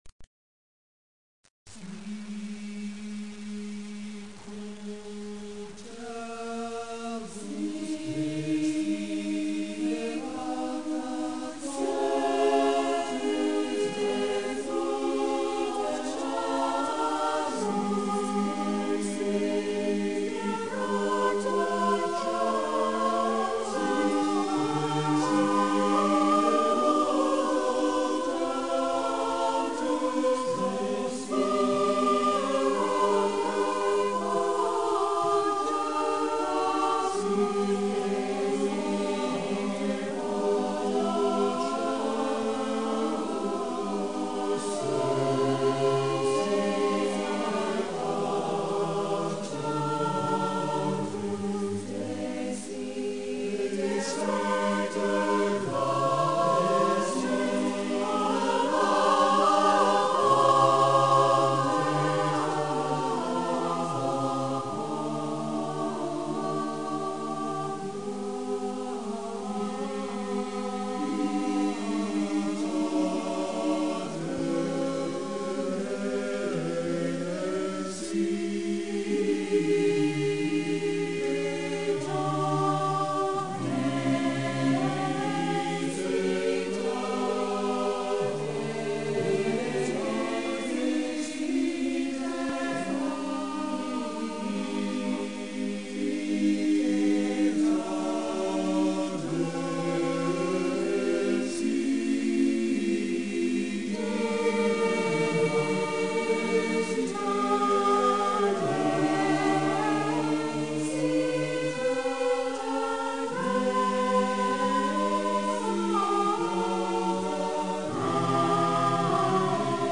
Play image ... 3 Mins 08 Seconds (1286 KB 56 Kbits/second 24,000 Hz Stereo), with an approx. loading time of 7 minutes (at about 3 KB/second).
(Radio)